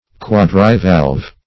Search Result for " quadrivalve" : The Collaborative International Dictionary of English v.0.48: Quadrivalve \Quad"ri*valve\, a. [Quadri- + valve: cf. F. quadrivalve.]